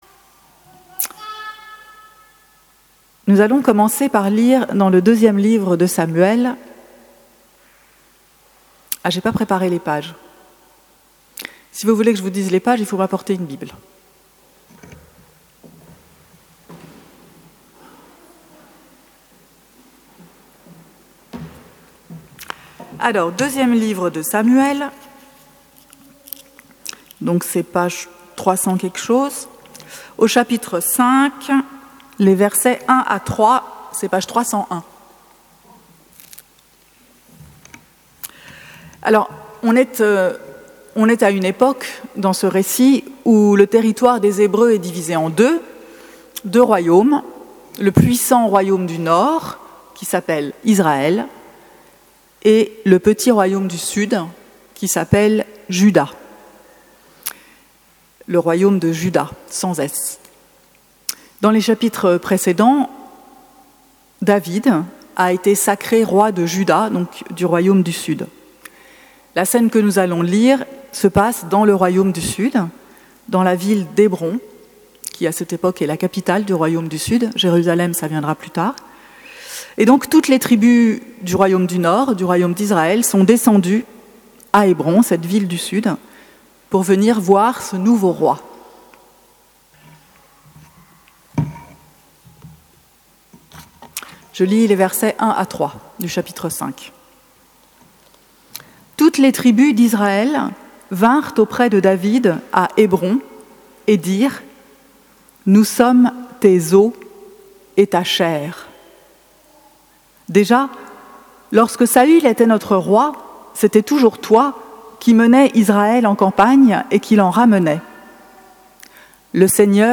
Ecouter les textes et la prédication
(début de la prédication : 10 minutes et 15 secondes)